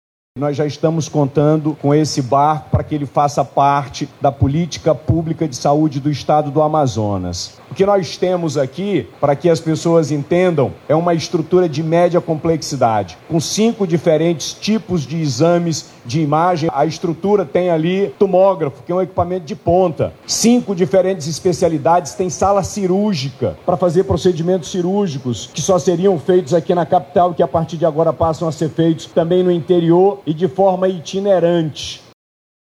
O Governo do Amazonas firmou um convênio e fará repasse mensal para garantir que as calhas dos rios sejam atendidas, bem como realiza um planejamento logístico junto com a associação. O governador, Wilson Lima, esteve presente inauguração do barco hospital.